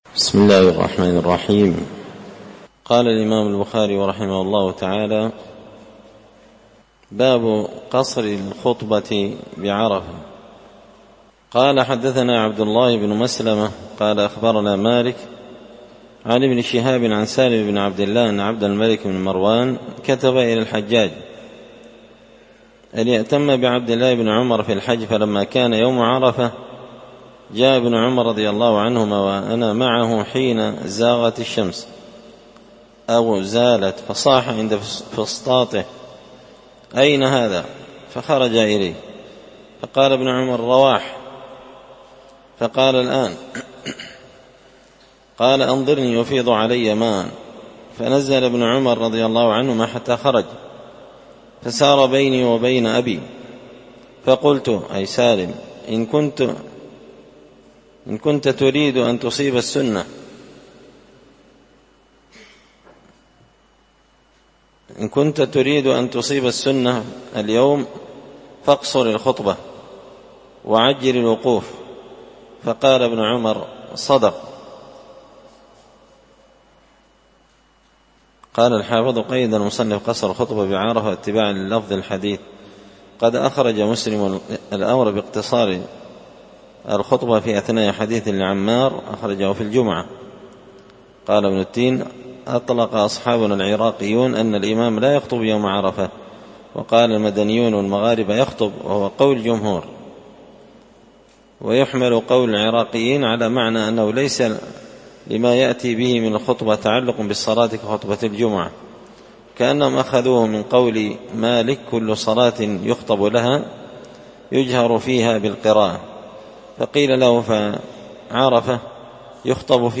ألقيت هذه الدروس في # دار الحديث السلفية بقشن بالمهرة اليمن مسجد الفرقان